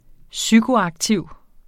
Udtale [ ˈsygo- ]